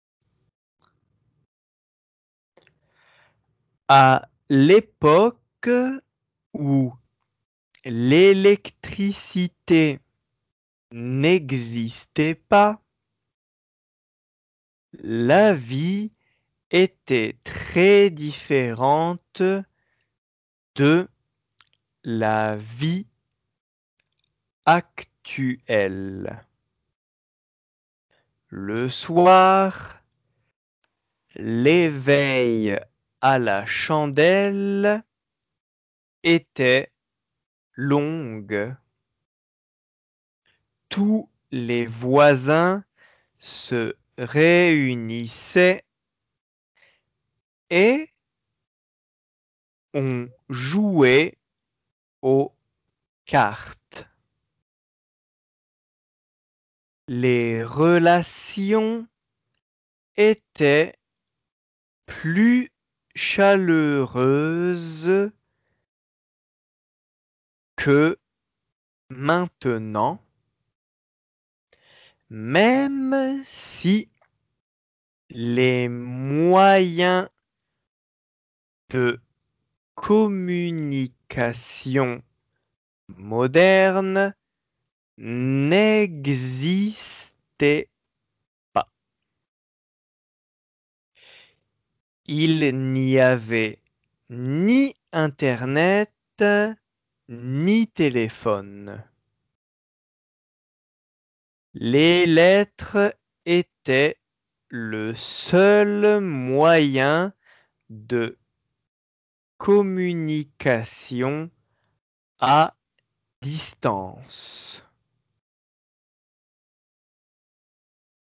仏検２級 デイクテー音声
デイクテ練習用です。　半過去が沢山出てくる表現です。　原形、複合過去、半過去も動詞の発音は一様に聞こえます。